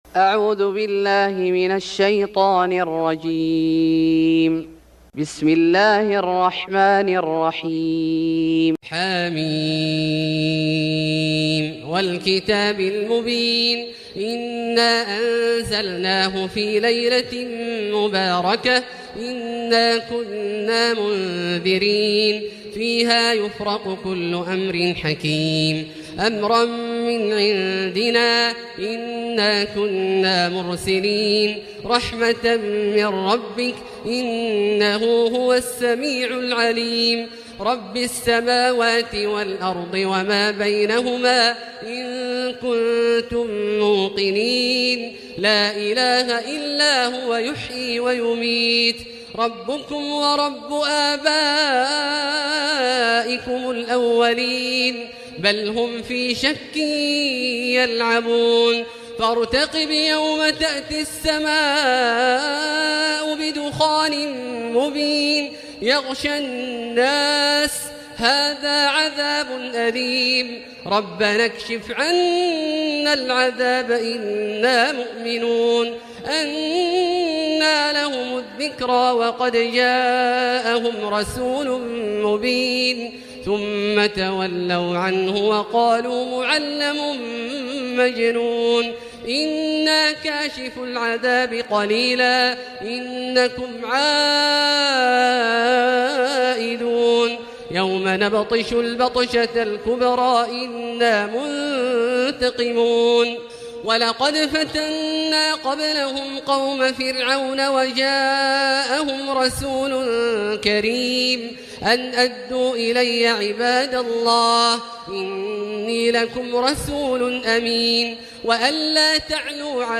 سورة الدخان Surat Ad-Dukhan > مصحف الشيخ عبدالله الجهني من الحرم المكي > المصحف - تلاوات الحرمين